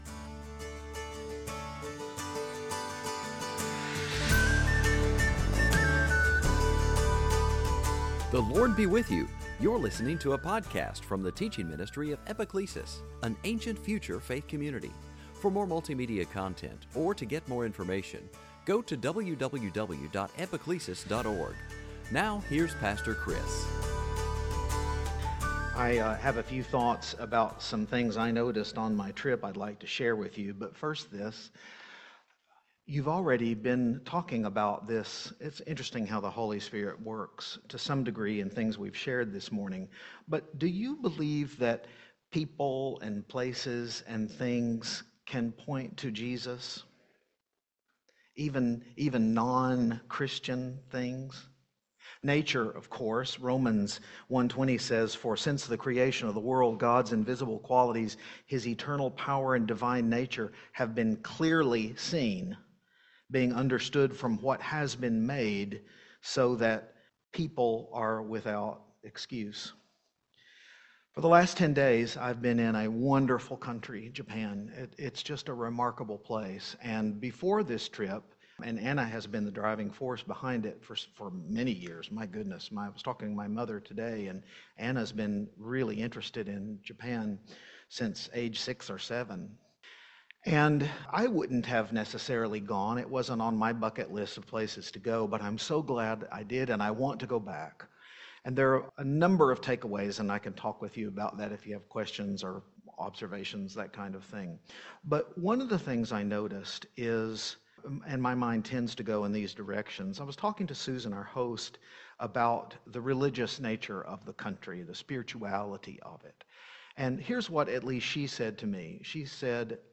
Service Type: Advent